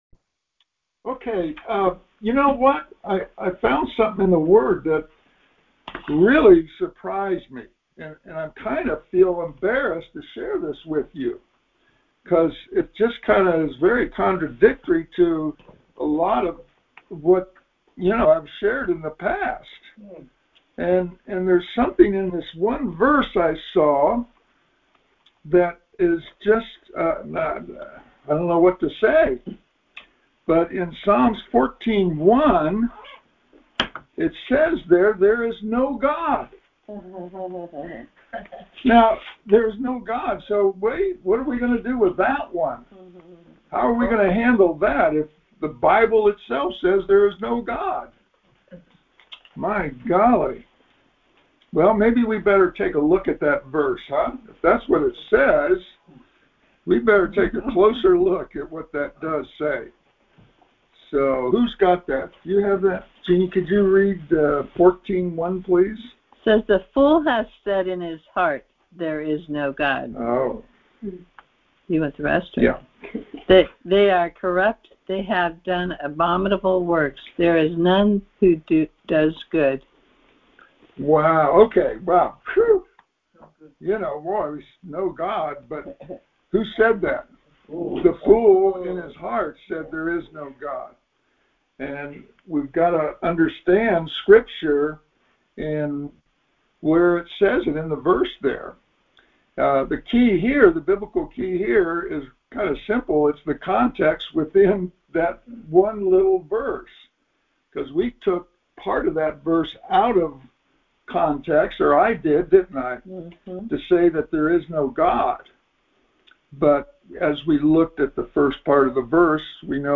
Series: Conference Call Fellowship